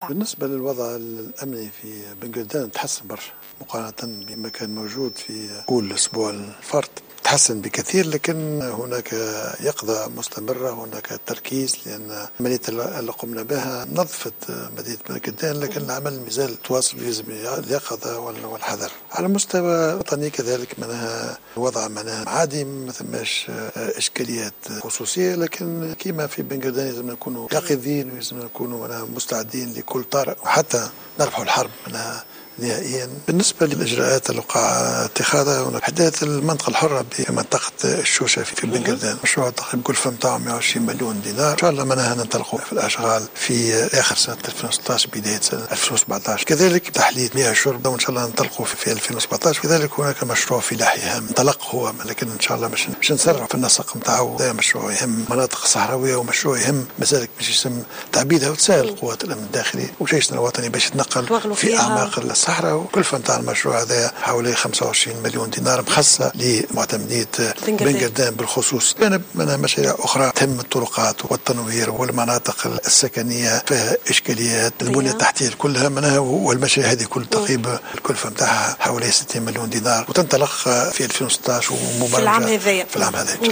كشف رئيس الحكومة الحبيب الصيد في مقابلة لقناة "حنبعل" الخاصة مساء اليوم الأربعاء، عن مجموعة من الإجراءات وصفها بـ "العاجلة" سيتم اتخاذها لفائدة معتمدية بنقردان.